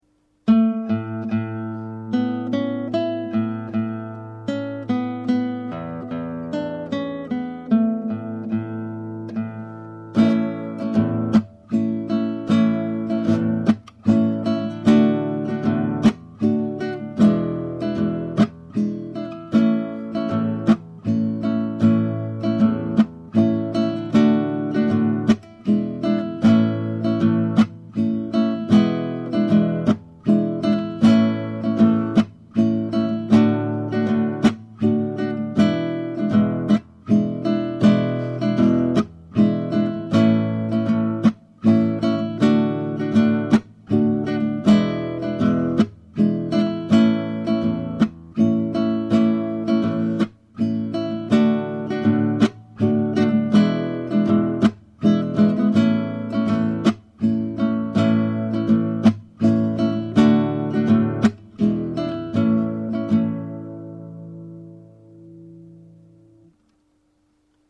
Aire de Pampeana: MP3 begeleiding met intro van 4 maten